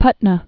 (pŭtnə)